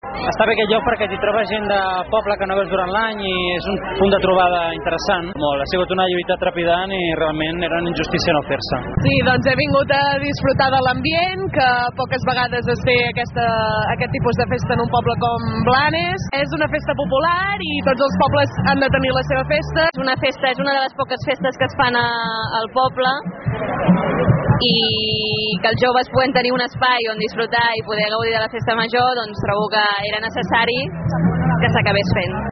Tot i això, els joves de Blanes i rodalies no van faltar en una cita pràcticament obligada.